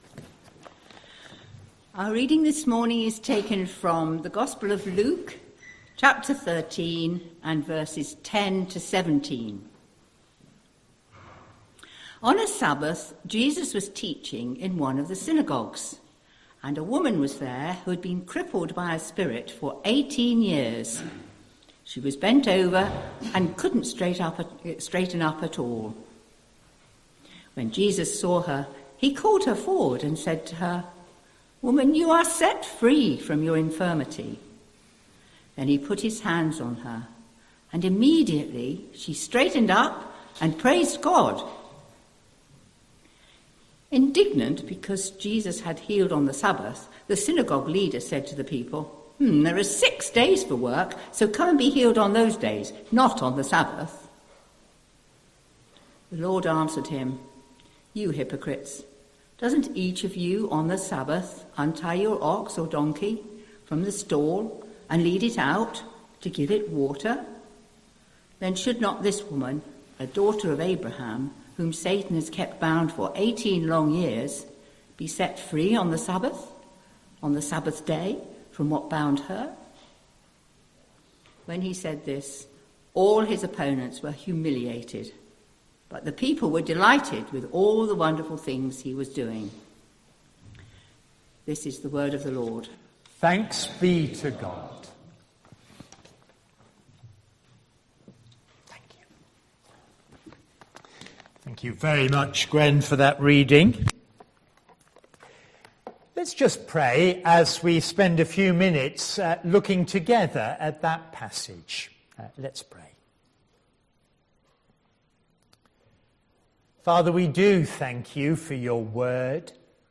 24th August 2025 Sunday Reading and Talk - St Luke's